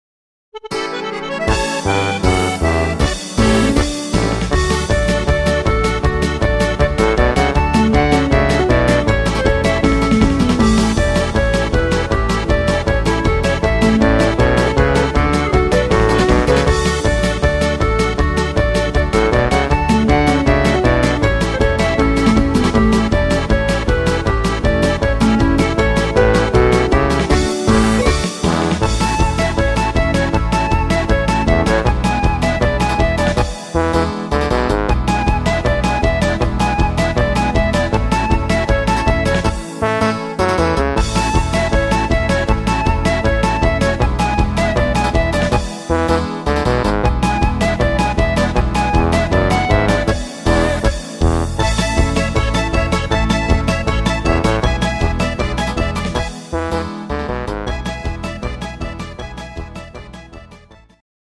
instr.